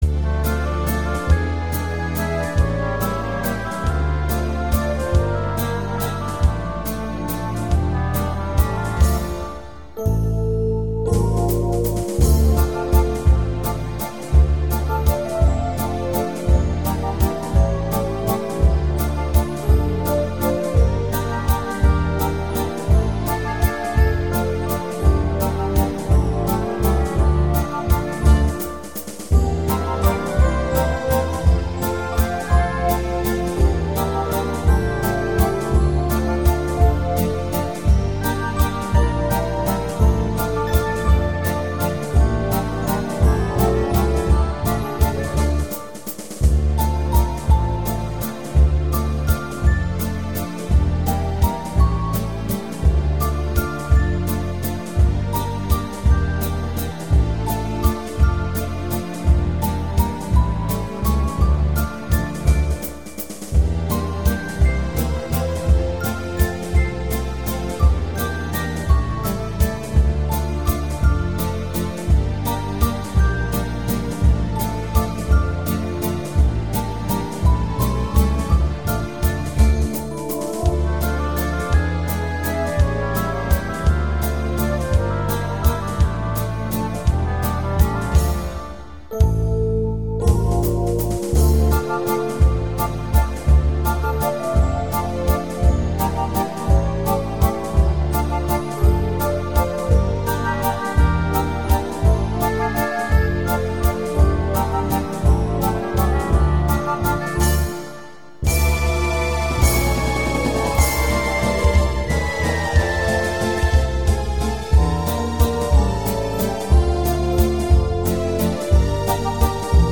Хотелось бы услышать старинный русский вальс "Ночь светла" в исполнении оркестра,лучше,конечно,духового и без вокала.Спасибо.
Есть минусовка без вокальной темы